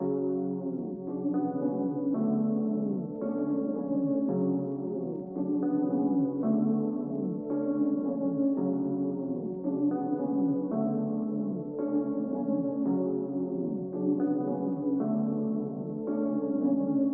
钢琴质量 未定义
标签： 112 bpm Trap Loops Piano Loops 2.88 MB wav Key : F